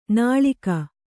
♪ nāḷika